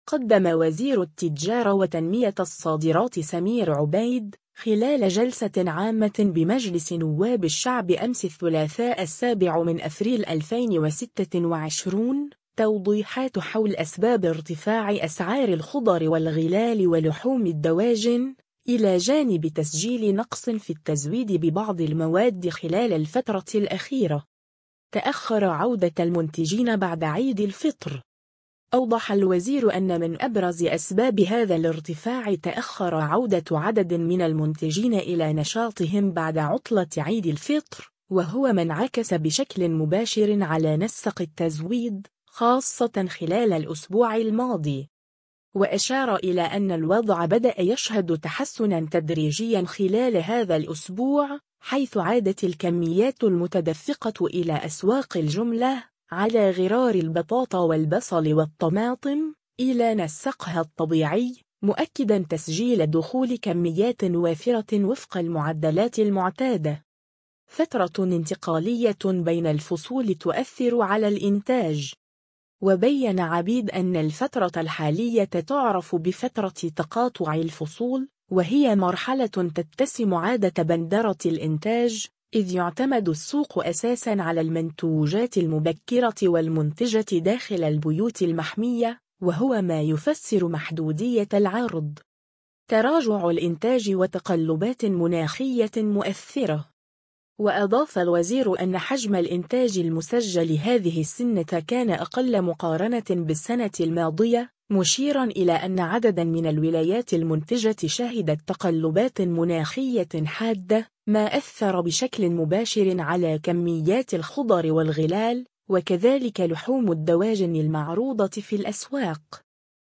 قدّم وزير التجارة وتنمية الصادرات سمير عبيد، خلال جلسة عامة بمجلس نواب الشعب أمس الثلاثاء 7 أفريل 2026، توضيحات حول أسباب ارتفاع أسعار الخضر والغلال ولحوم الدواجن، إلى جانب تسجيل نقص في التزويد ببعض المواد خلال الفترة الأخيرة.